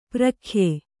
♪ prakhye